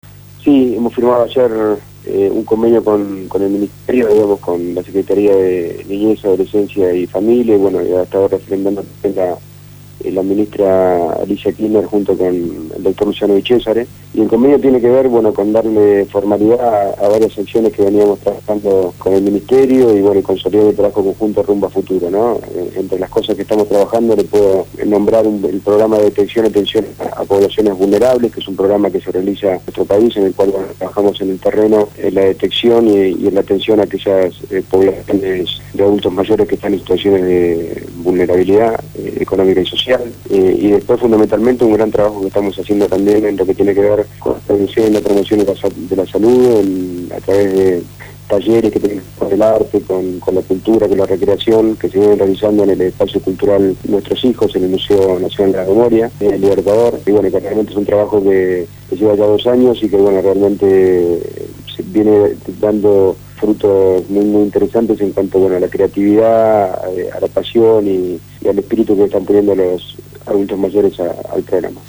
Federico Susbielles Gerente de Promoción Social y Comunitaria de la obra social de los jubilados, PAMI, fue entrevistado en el programa «Punto de partida» (Lunes a viernes de 7 a 9 de la mañana) por Radio Gráfica; en donde dio detalles del reciente convenio firmado con la secretaría de niñez, adolescencia y familia.